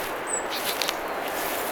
tuollainen talitiaislinnun huomioääni
tuollainen_talitiaislinnun_huomioaani.mp3